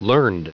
Prononciation du mot learned en anglais (fichier audio)
Prononciation du mot : learned